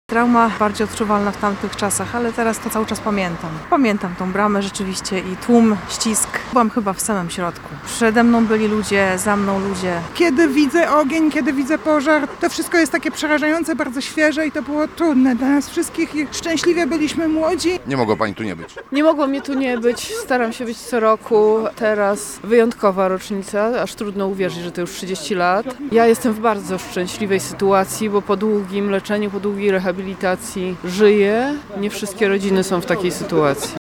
Świadkowie mówią mówią, że obrazy tragicznego w skutkach pożaru wciąż mają przed oczami: